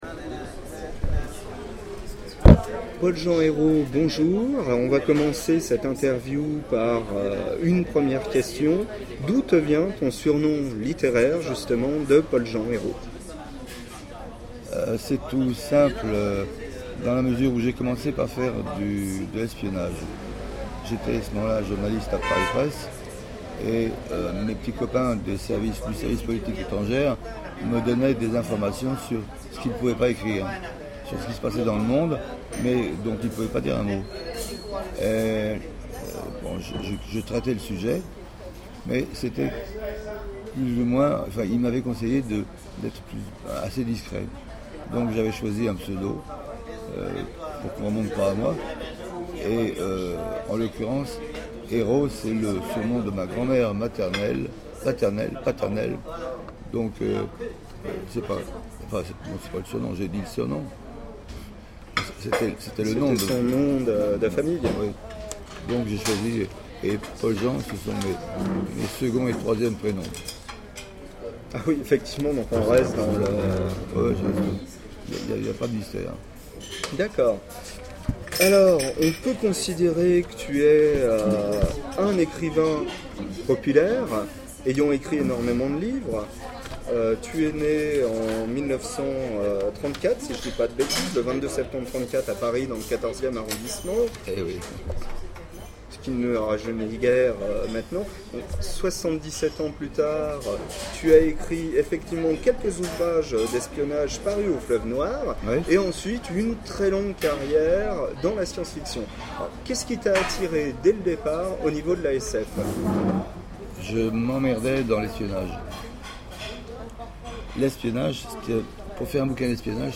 Une interview audio